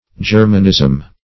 Germanism \Ger"man*ism\, n. [Cf. F. germanisme.]